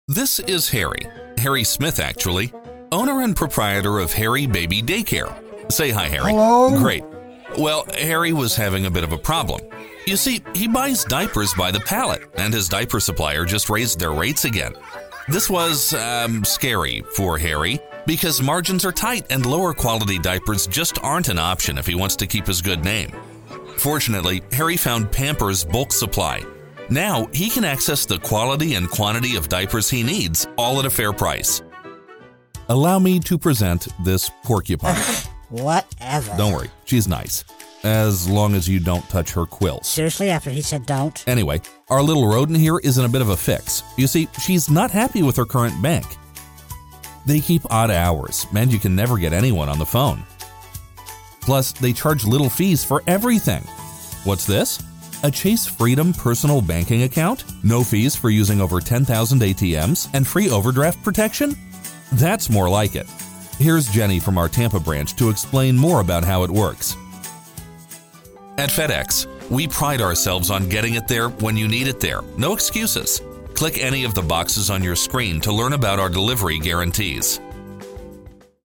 standard us
explainer video